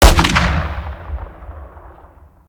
weap_br2_fire_plr_atmo_ext1_08.ogg